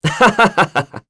Esker-Vox_Happy3.wav